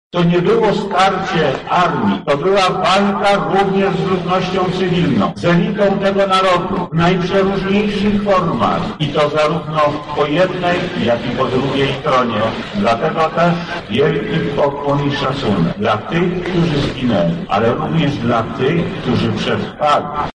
W czasie uroczystości głos zabrał m. in. wojewoda lubelski Lech Sprawka: